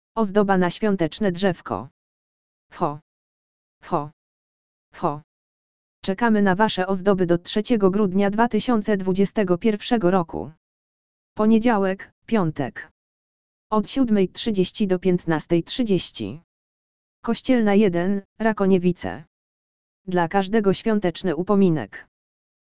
audio_lektor_ozdoba_na_swiateczne_drzewko_!!.mp3